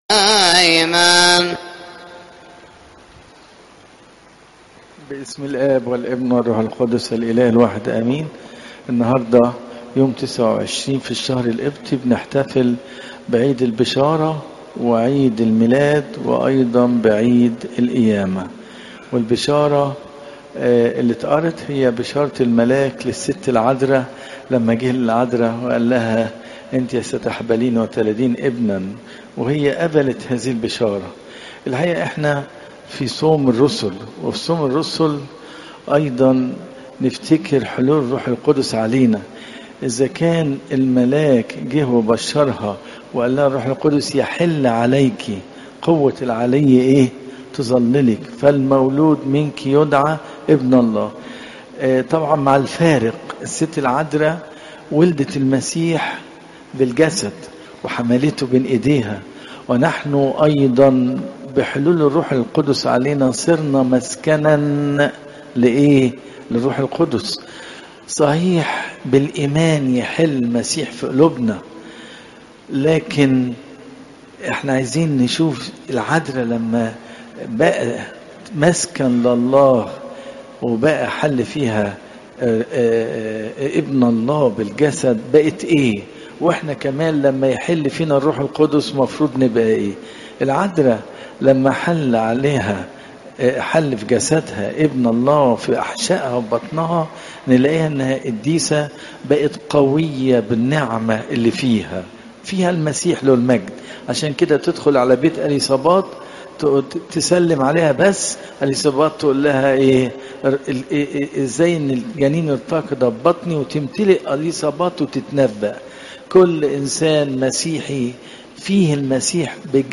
عظات قداسات الكنيسة